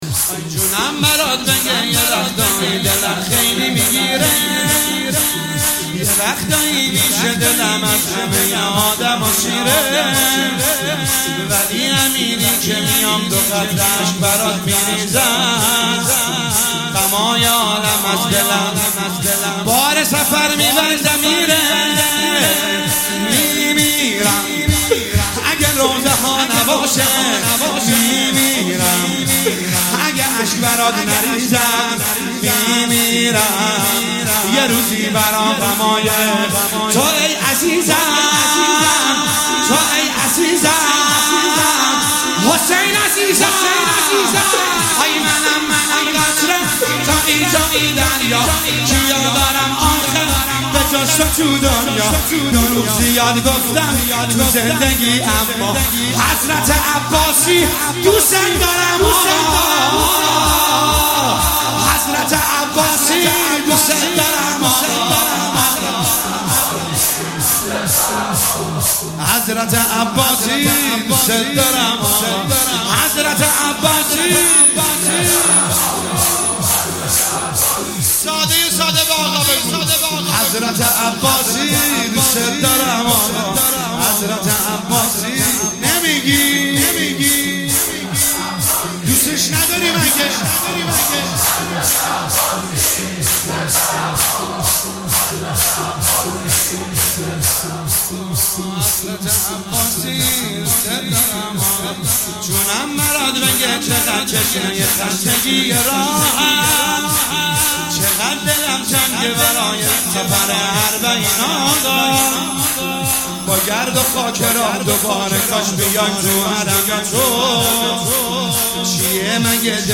شب هفتم محرم1401
شور